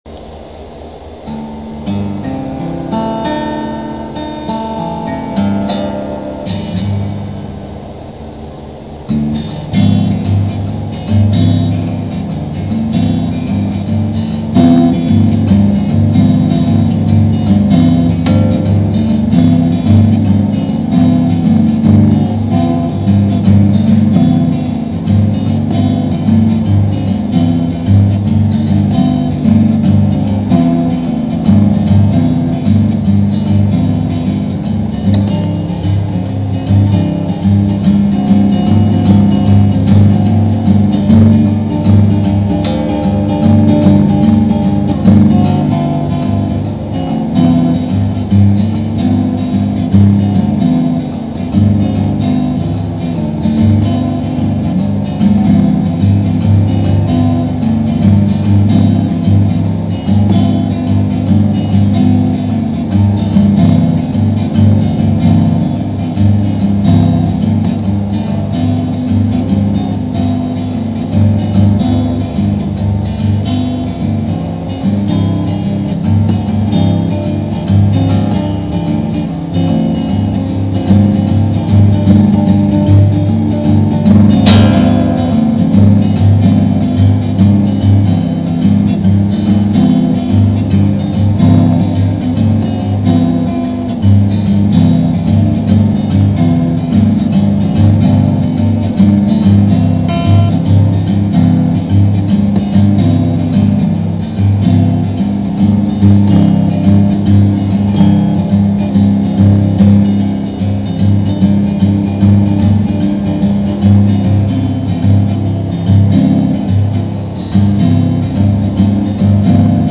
rough acoustic
acoustic.wav